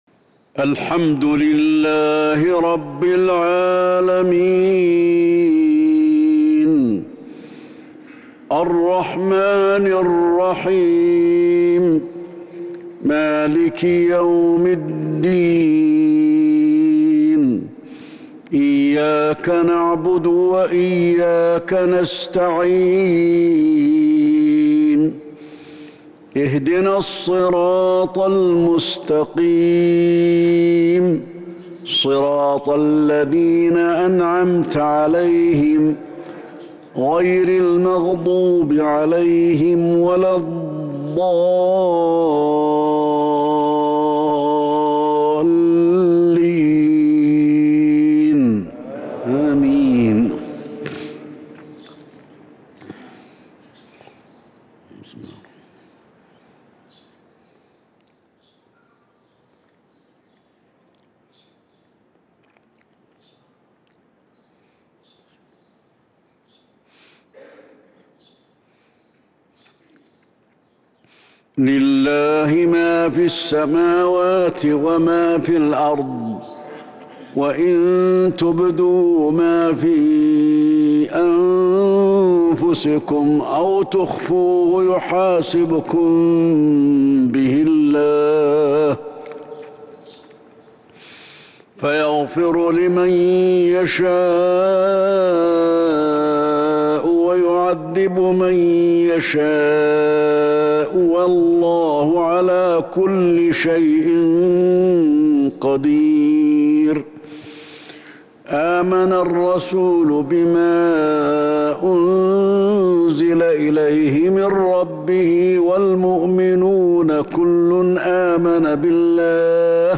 صلاة المغرب 6-9-1442هـ سورتي البقرة والمؤمنون | Maghrib prayer Surah Al-Baqara and Al-Muminoon 18/4/2021 > 1442 🕌 > الفروض - تلاوات الحرمين